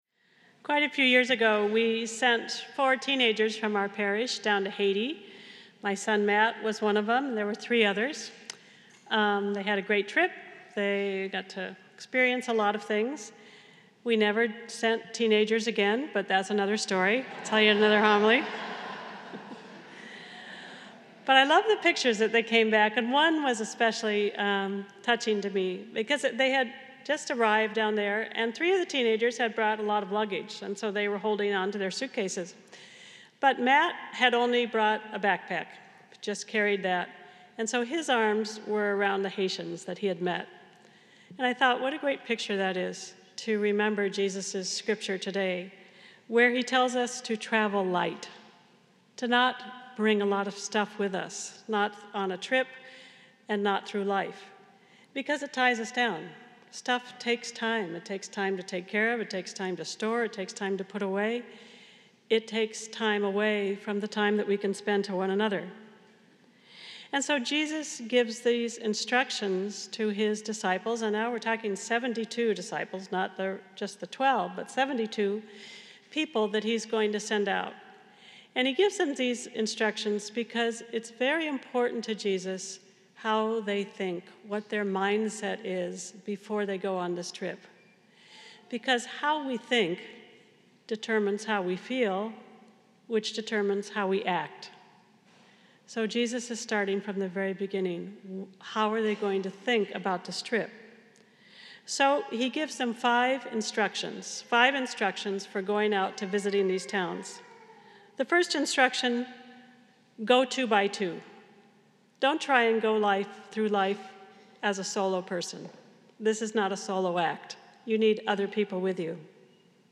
Homily Transcript Quite a few years ago we sent 4 teenagers from our parish down to Haiti.